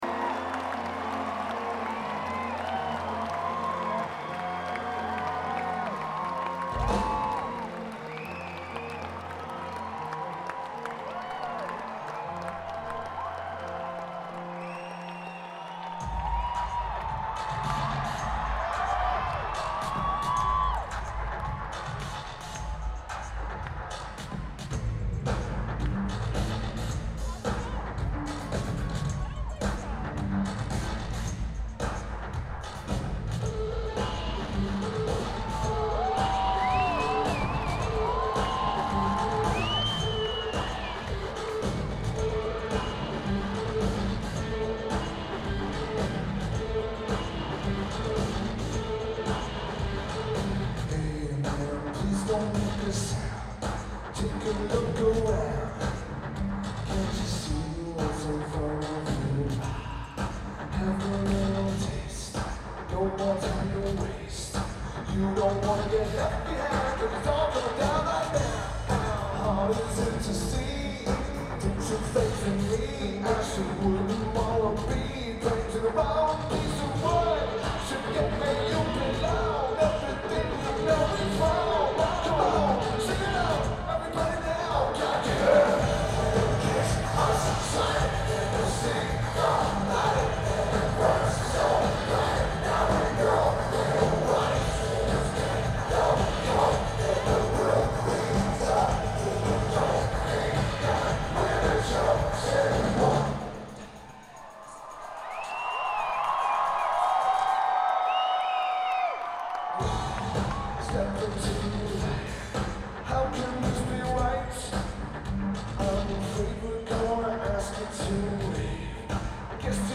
Air Canada Centre
Toronto, ON Canada
Drums
Bass
Guitar